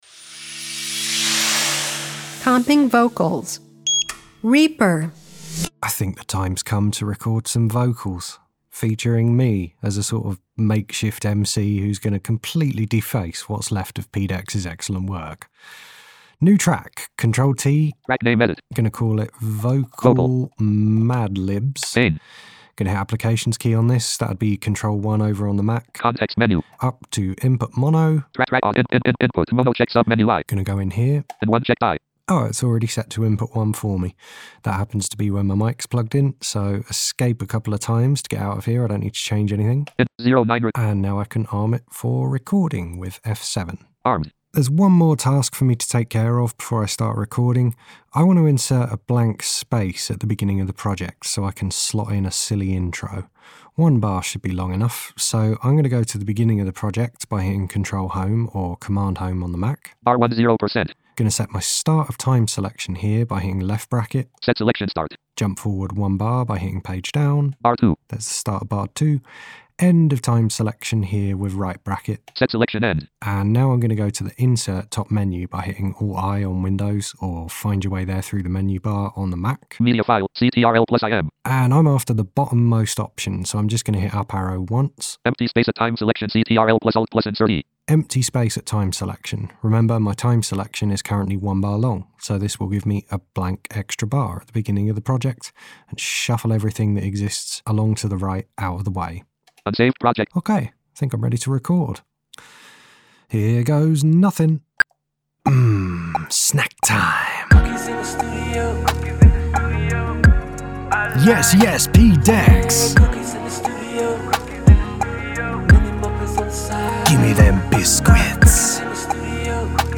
Each task is performed in each DAW and the listener gets an idea of the UI layout, feedback, etc. In total, there’s over 4 hours of material.